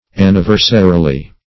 anniversarily - definition of anniversarily - synonyms, pronunciation, spelling from Free Dictionary Search Result for " anniversarily" : The Collaborative International Dictionary of English v.0.48: Anniversarily \An`ni*ver"sa*ri*ly\, adv.
anniversarily.mp3